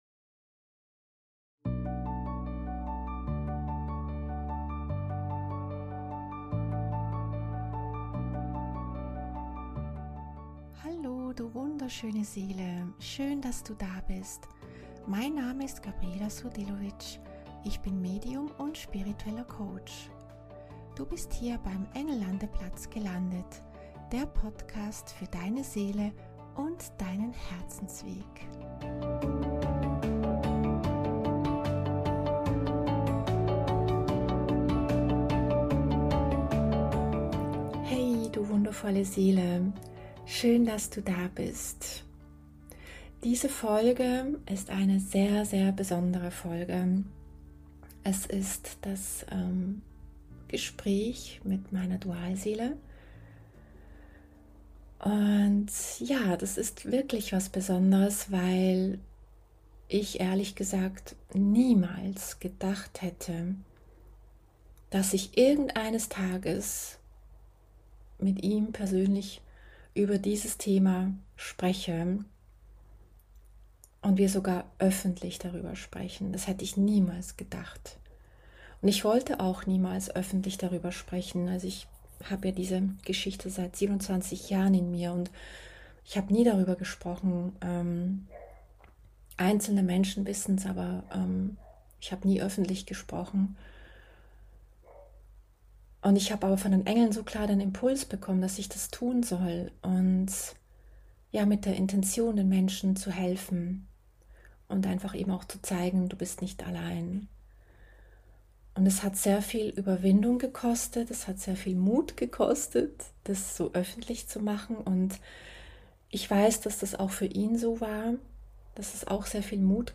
In dieser Folge kommt nun endlich das langersehnte Gespräch mit meiner Dualseele. Wir haben seine Sichtweise beleuchtet und darüber gesprochen, wie er die Zeit erlebt hat. Wir waren super nervös, aber ich hoffe wir konnten alles beleuchten.